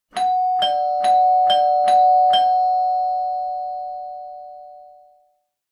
Doorbell (long)